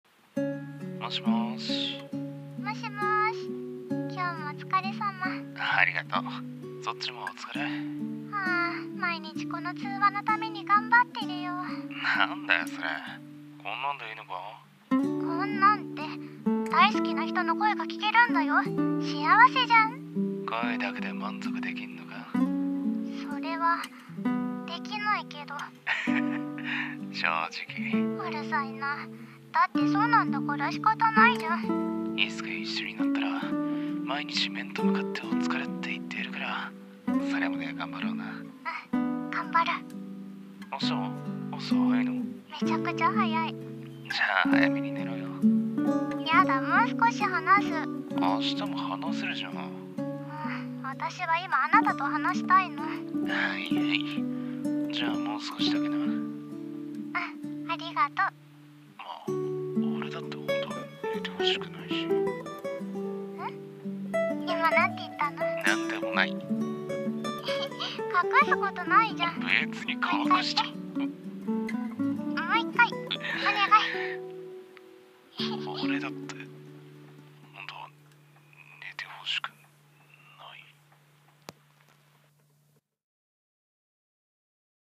【声劇台本】寝る前に聴く、君の声【掛け合い】